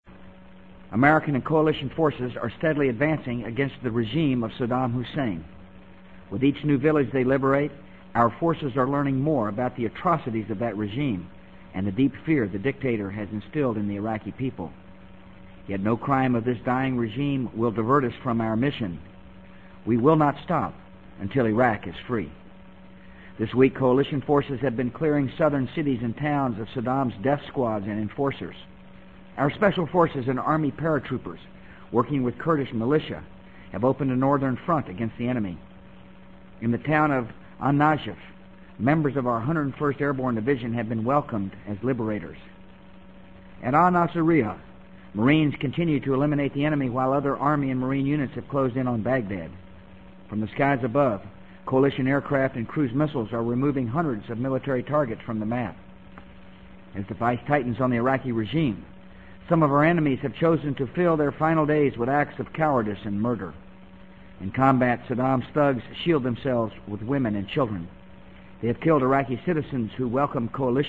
【美国总统George W. Bush电台演讲】2003-04-05 听力文件下载—在线英语听力室